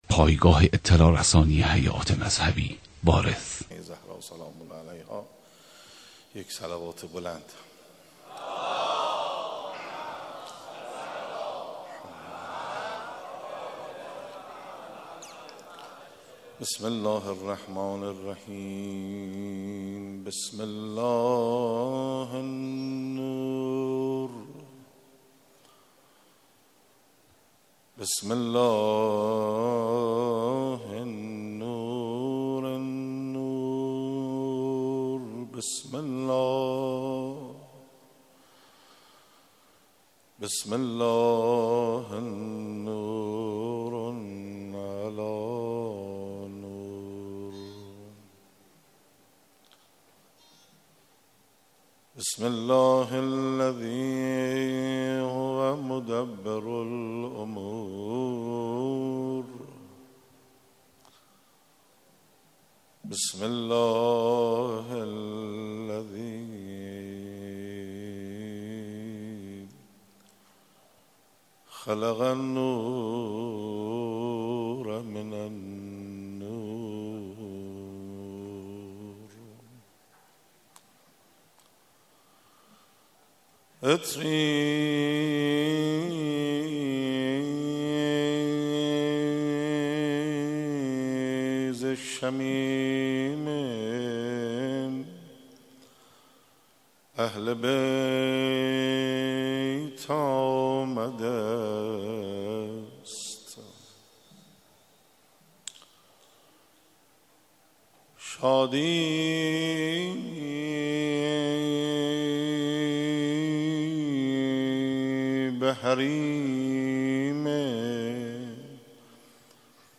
حجت الاسلام انصاریان: از گناهانتان دست بردارید / مولودی خوانی
مراسم روز پانزدهم ماه مبارک رمضان با سخنرانی حجت الاسلام استاد انصاریان
در مسجد حضرت امیر (ع) برگزار شد.